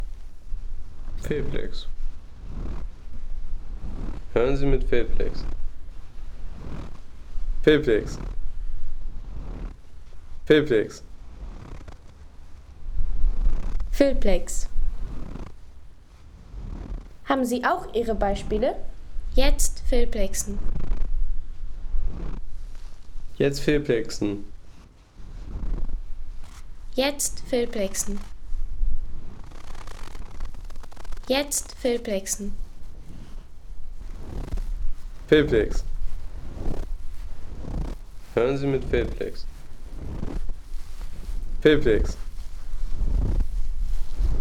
Knirschender Schnee auf dem Gipfel
Natur - Schnee
Knirschender Schnee auf dem Gipfel – Geräusch des ewigen Winters.